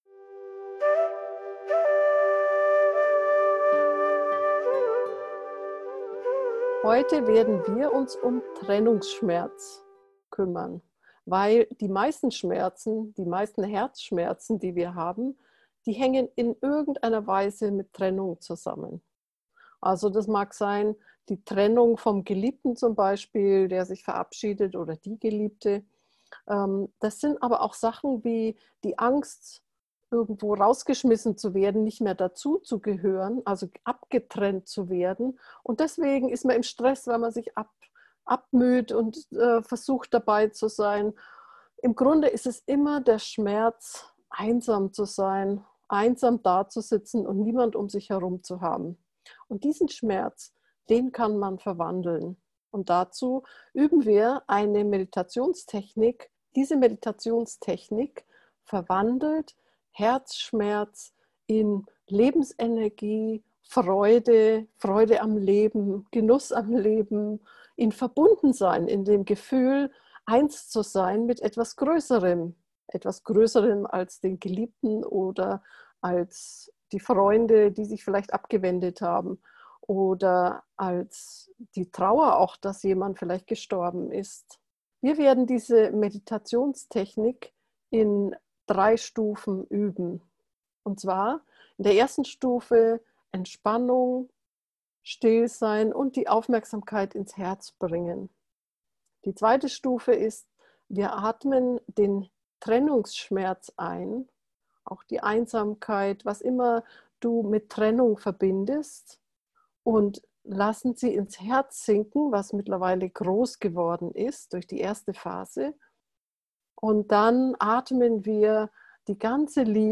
verwandlung-trennungsschmerz-gefuehrte-meditation